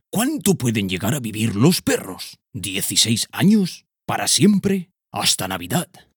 TEST HISTORIA PERRO-Narrador-02.ogg